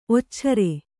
♪ occare